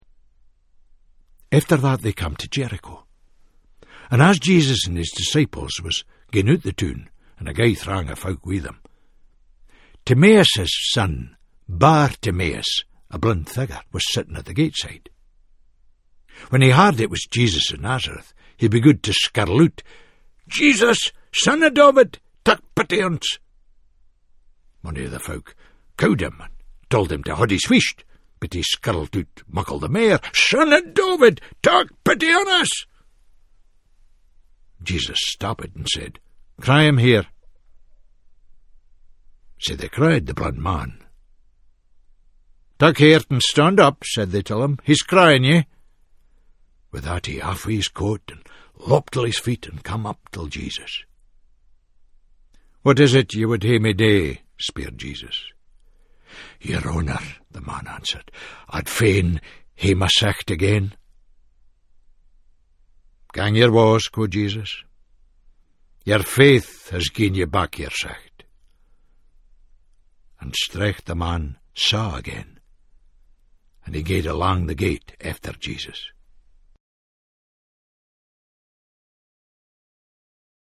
In the recordings of the Scots New Testament, Tom Fleming brings the well known stories of the gospels to life in a distinct Scots voice.